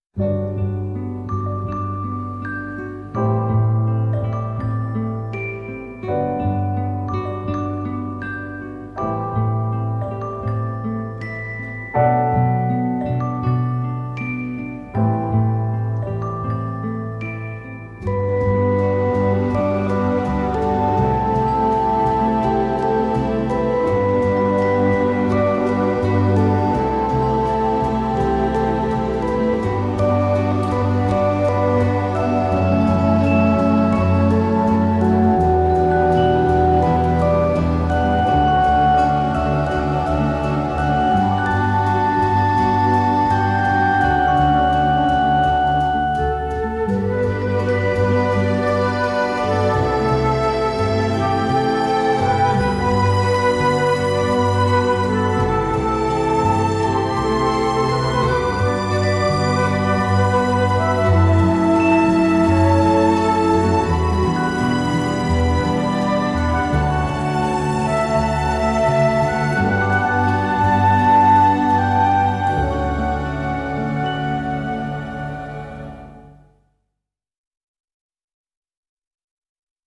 radiomarelamaddalena / STRUMENTALE / ORCHESTRE /
Original Motion Picture Soundtrack